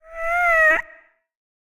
Minecraft Version Minecraft Version 25w18a Latest Release | Latest Snapshot 25w18a / assets / minecraft / sounds / mob / ghastling / ghastling6.ogg Compare With Compare With Latest Release | Latest Snapshot
ghastling6.ogg